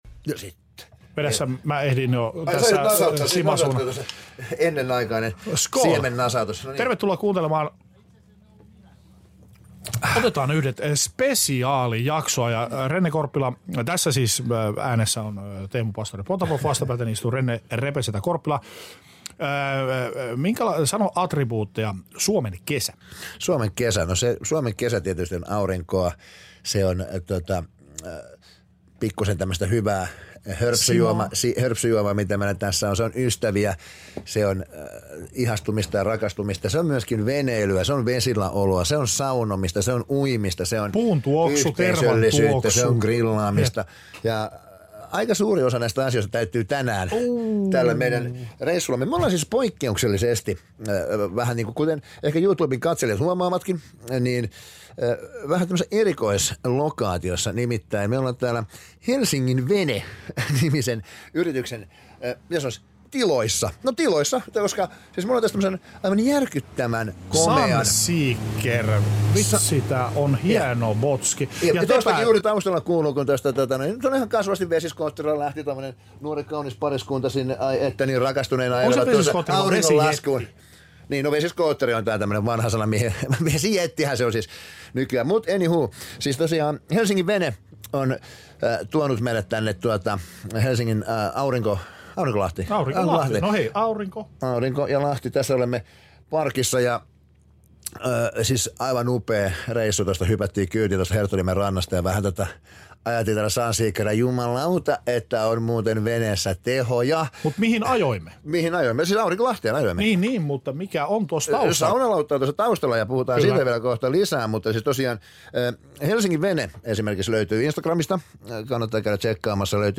Tällä kertaa Team OY on päätynyt veneilemään ja saunalautalle saunomaan ja siinä sivussa muistelemaan omia lapsuusmuistojaan vesistöjen ääreltä sekä pohtimaan myös kansamme suhdetta luontoon, saunaan ja kaikkeen muuhunkin kansallisromanttiseen.